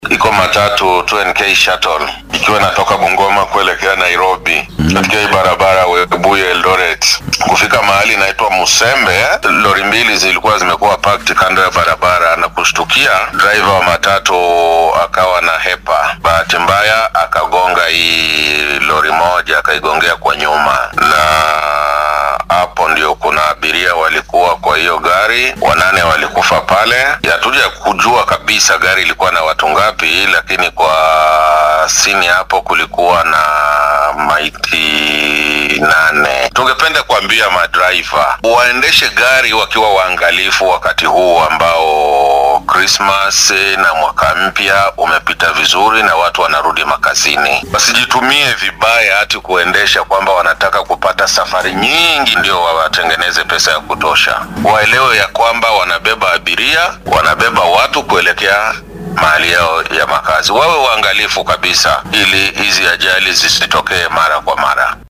Taliyaha booliska ee ismaamulka Kakamega Xasan Barua oo khadka taleefoonka ugu warramay idaacadda Radio Citizen ayaa faahfaahin ka bixiyay shilka saaka dhacay